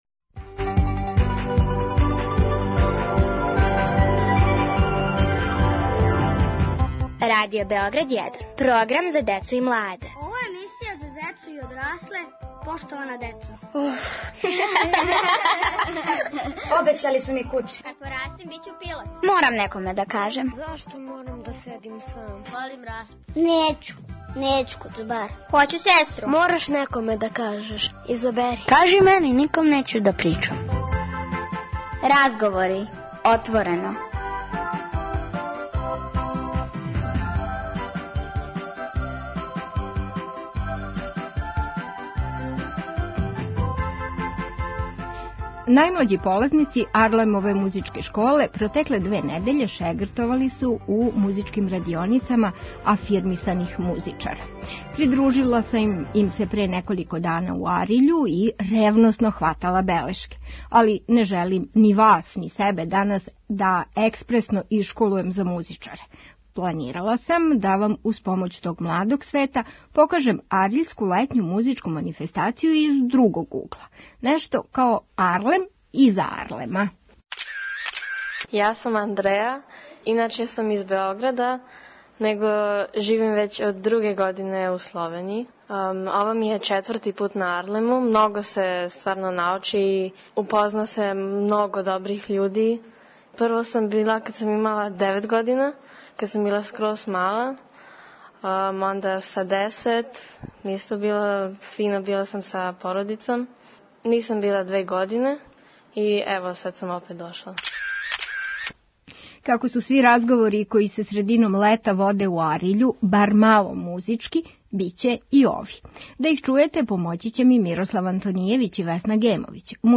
Учесници Ариљске летње музичке манифестације, полазници музичких и разних других радионица, волонтери и организатори, отворено разговарају о АРЛЕММ-у иза АРЛЕММ-а.